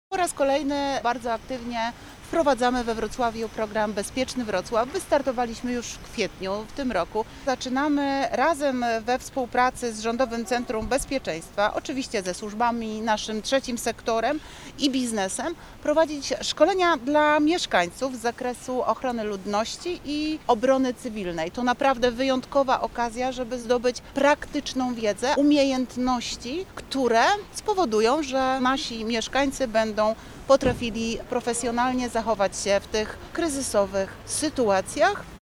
– To kolejny element programu „Bezpieczny Wrocław” realizowanego od kwietnia – mówi wiceprezydent Wrocławia, Renata Granowska.